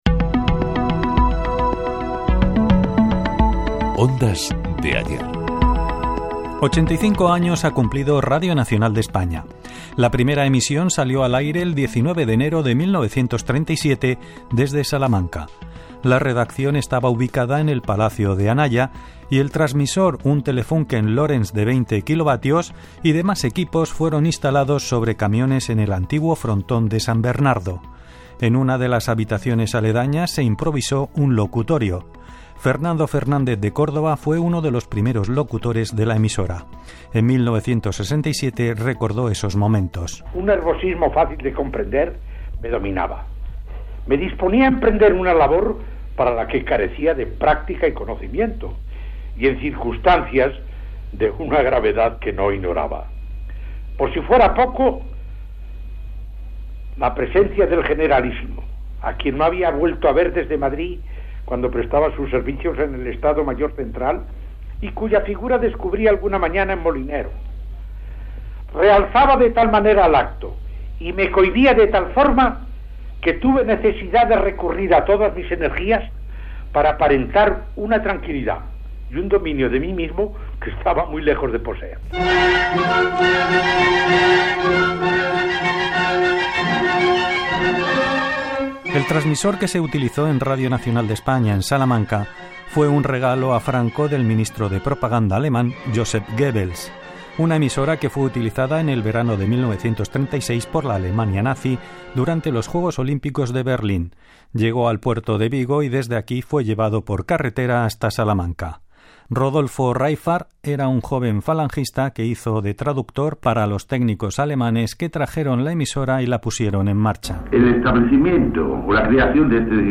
Careta i espai dedicat als 85 anys de l'inici de les emissions de Radio Nacional de España des de Salamanca, amb declaracions del locutor Fernando Fernández de Córdoba (de l'any 1967)
Divulgació